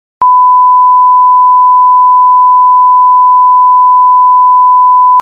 som-de-censura-download.mp3